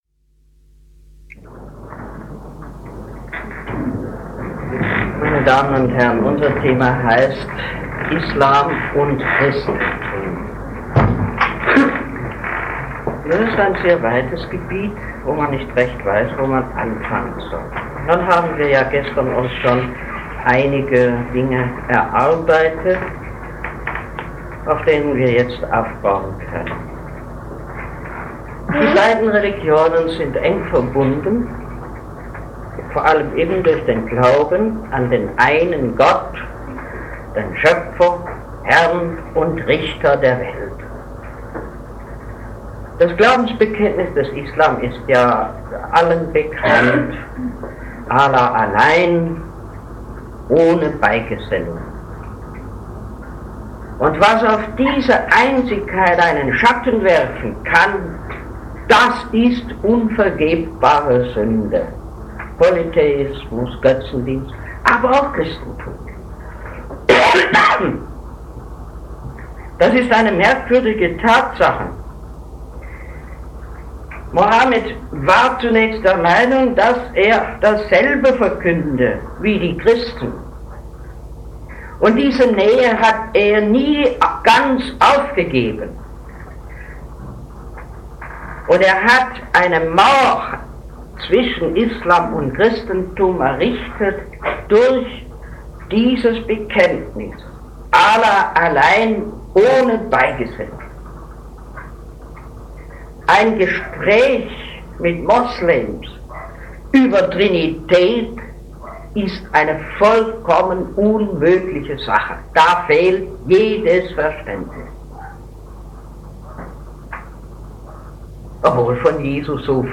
Vortrag
auf der Tagung der Katholischen Akademie der Erzdiözese Freiburg „Christentum und Islam" am 7. Juni 1959 im Hotel Pfalz in Neckargemünd.